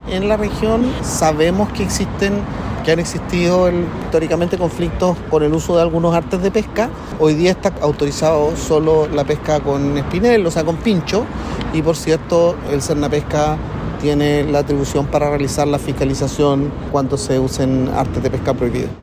Consultado por este fenómeno, el subsecretario de Pesca, Julio Salas, aseguró estar en conocimiento y apuntó a la atribución tiene entidades fiscalizadoras.